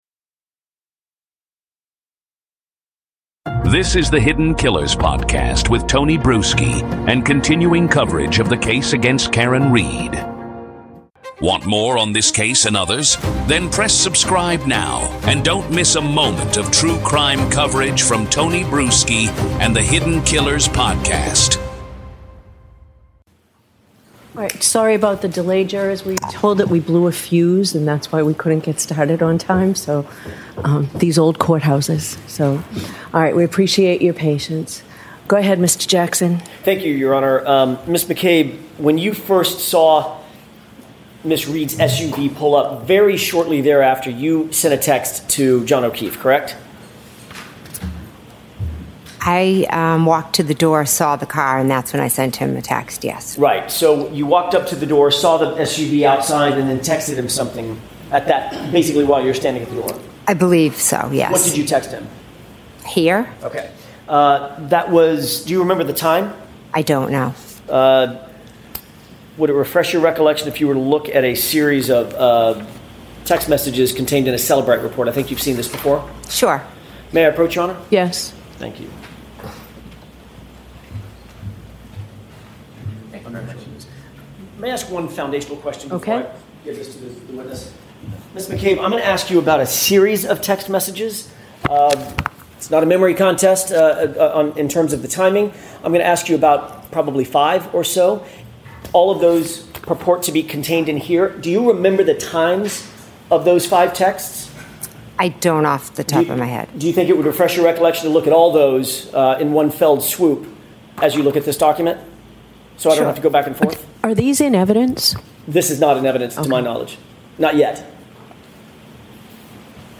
Listen to the full trial audio and decide for yourself.
Complete trial audio – no edits, no spin.